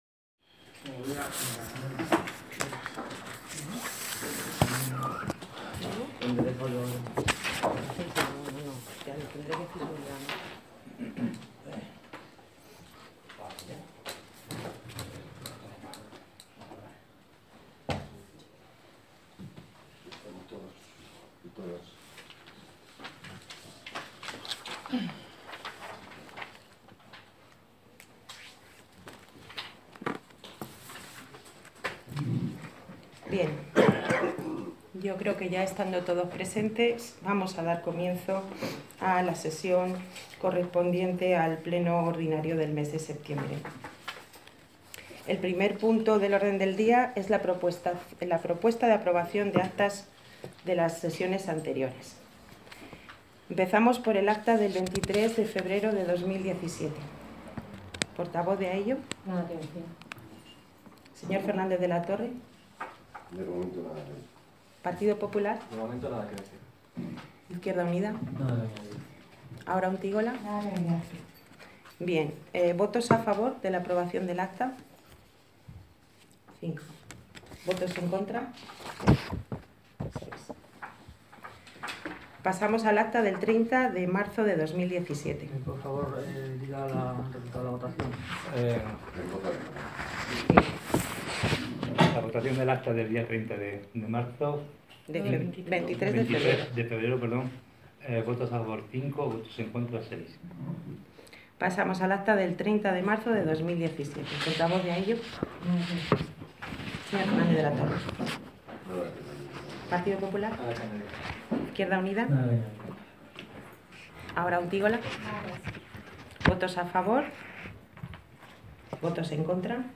Audio del Pleno Ordinario de 2 de octubre de 2017